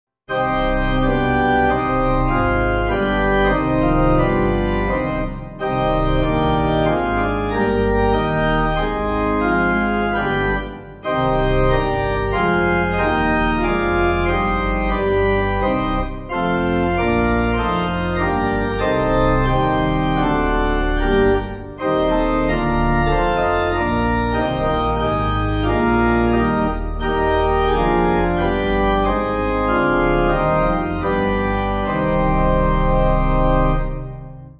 Organ
(CM)   4/Cm